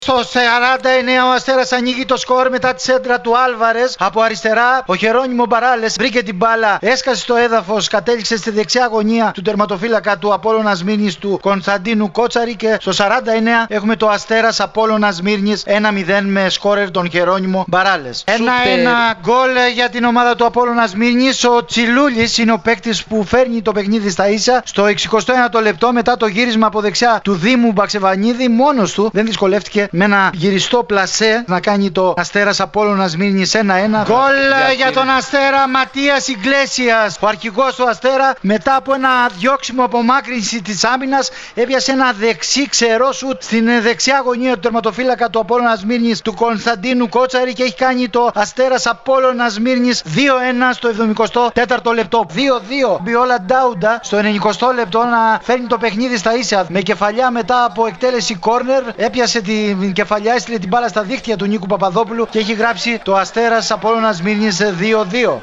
Ακούστε τα γκολ που σημειώθηκαν στα παιχνίδια της τελευταίας αγωνιστικής των έτσι όπως μεταδόθηκαν από την συχνότητα της ΕΡΑΣΠΟΡ.